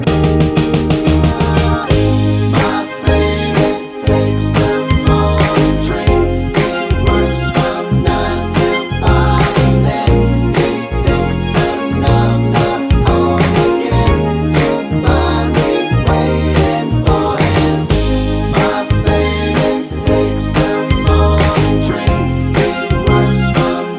Minus Saxophone Soundtracks 3:24 Buy £1.50